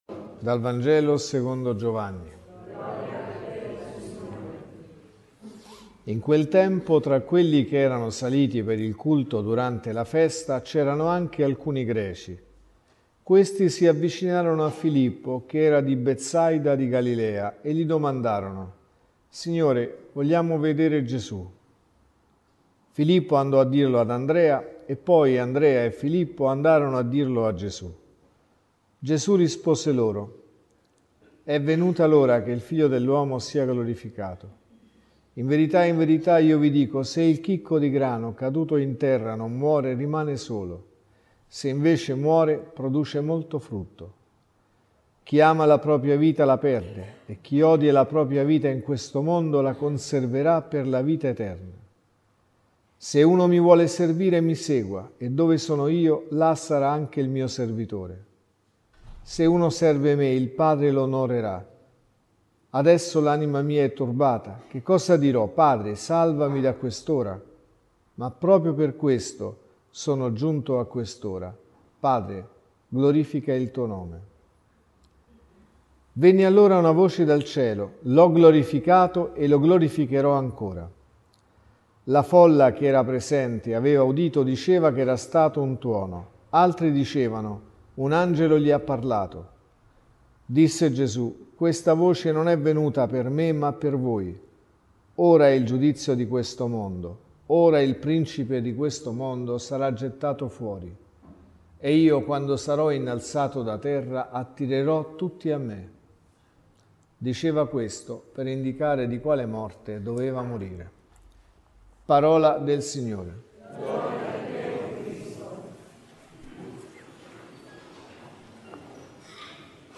Omelie Messa della mattina